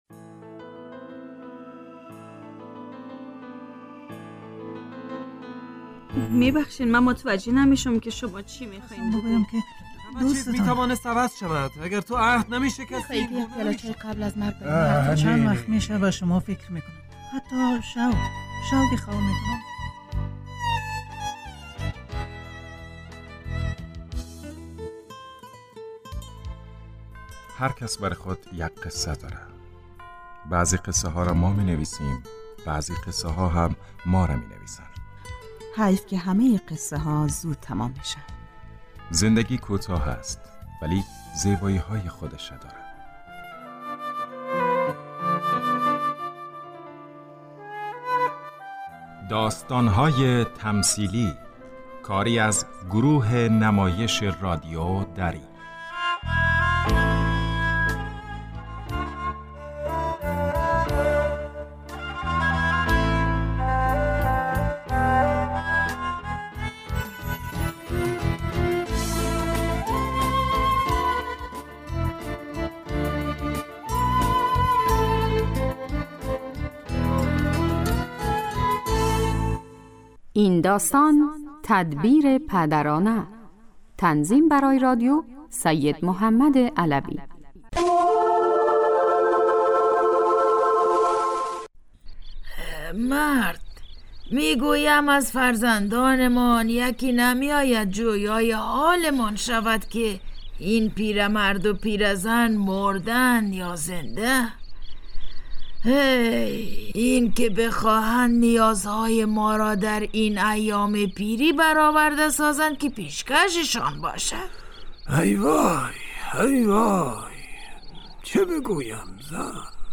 داستان تمثیلی / تدبیر پدرانه